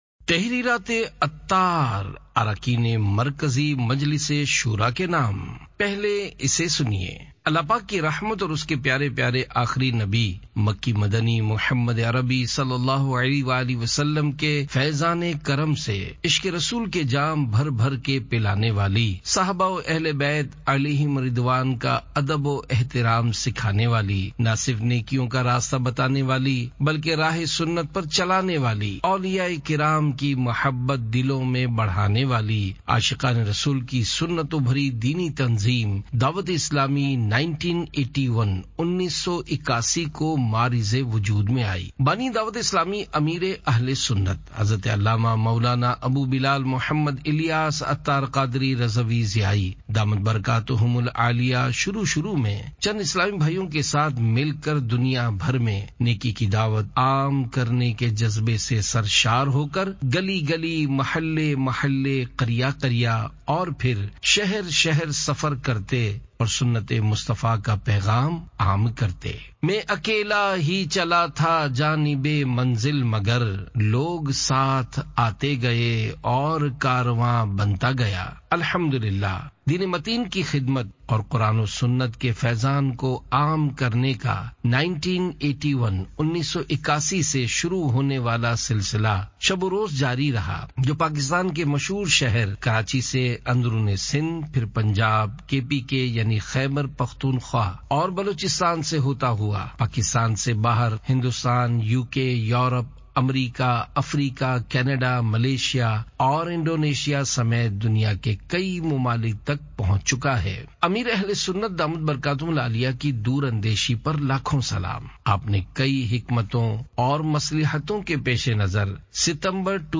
Audiobook - Tahreerat e Attar (Uradu)